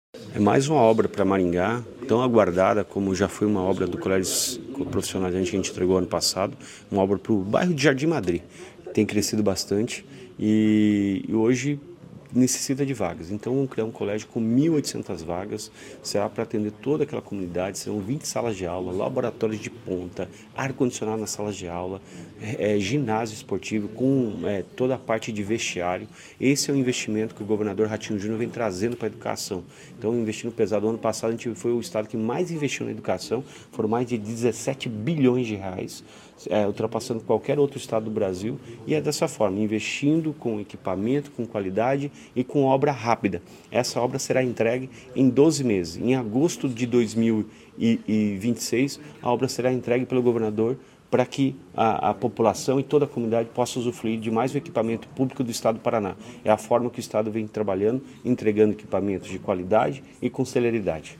Sonora do secretário da Educação, Roni Miranda, sobre o anúncio da construção de uma nova escola estadual em Maringá